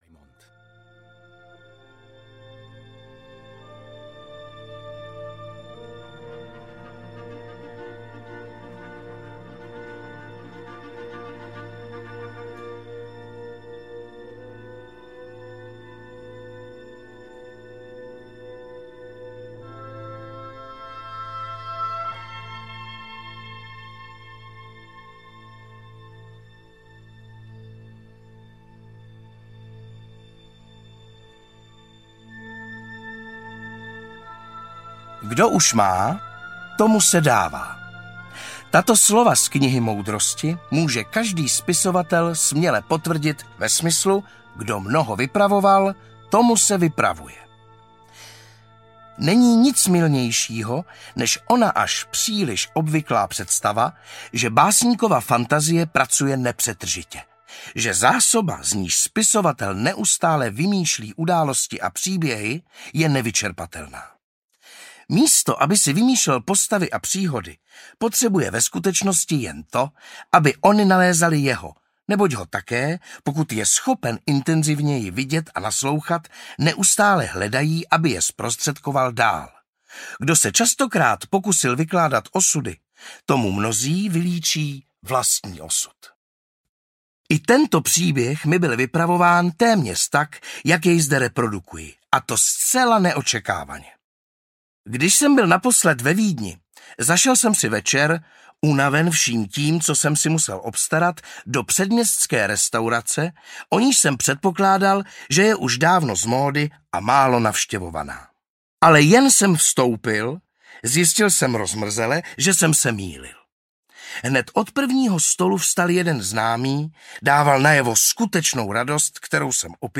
Netrpělivost srdce audiokniha
Ukázka z knihy